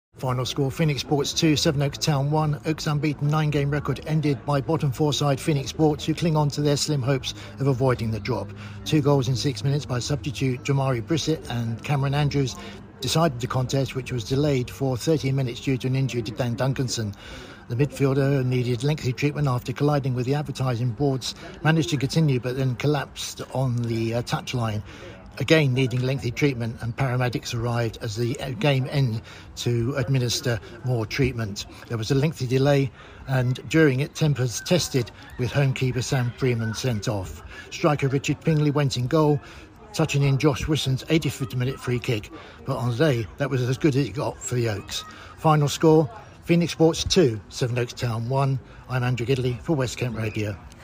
You can hear regular updates on the local sport during Saturday Action on West Kent Radio from 2pm Saturdays.